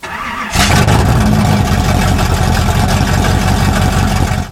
V8StarUp